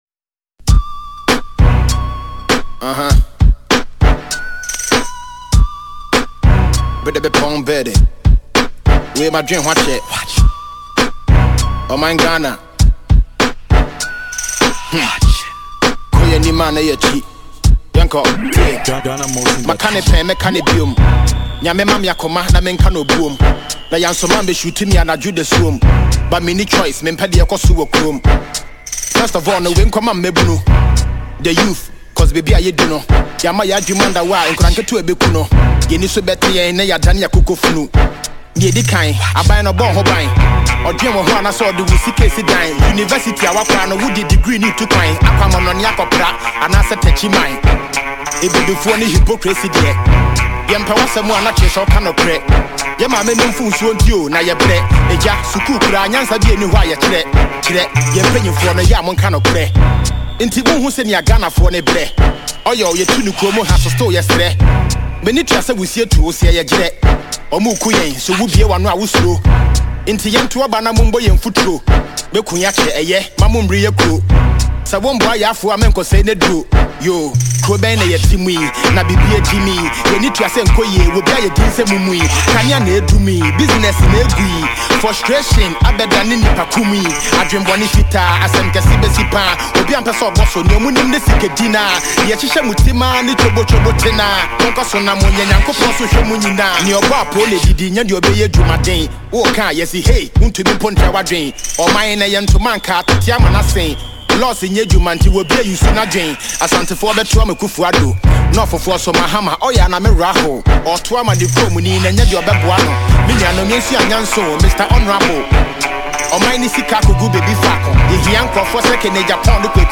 Ghanaian Rapper
Scathing political Track